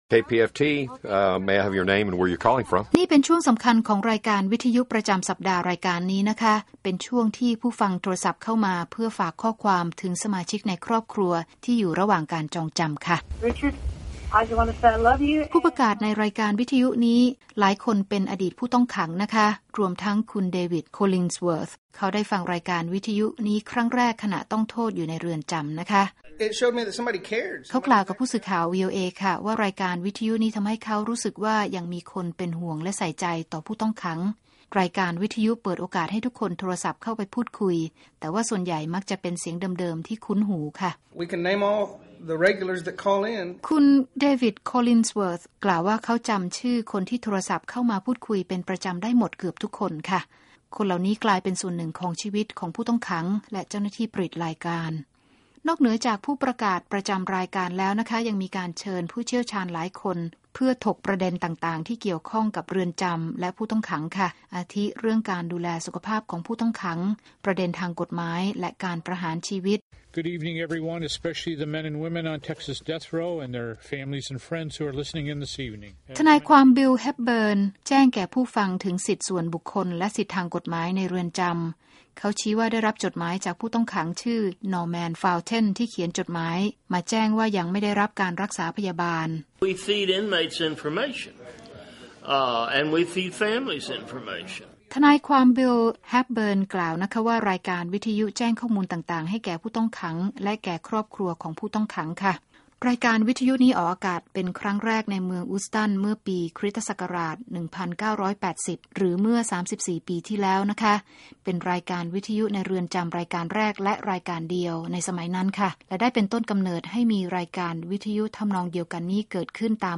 ช่วงสำคัญของรายการวิทยุประจำทุกสัปดาห์รายการนี้เป็นช่วงที่ผู้ฟังโทรศัพท์เข้าไปฝากข้อความถึงสมาชิกครอบครัวที่อยู่ระหว่างการจองจำ